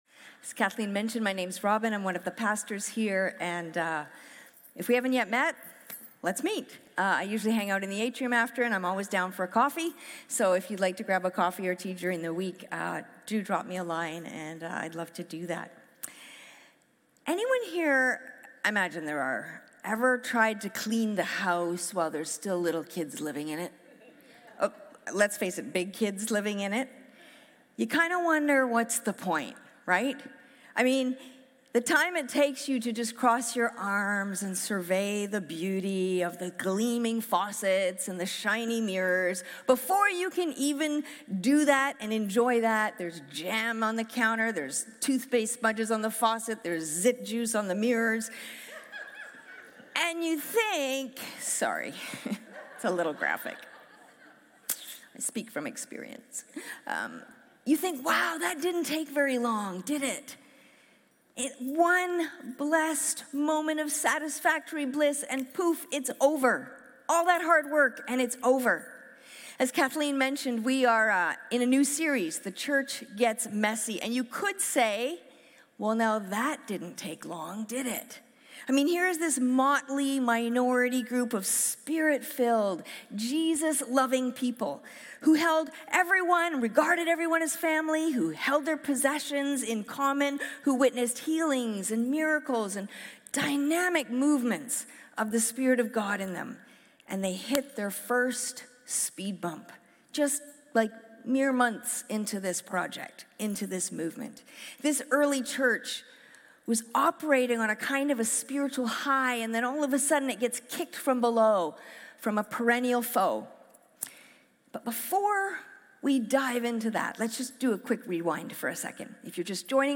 That’s what it felt like in the early church - it was beautiful for a moment and then chaos crashed in. In this week’s message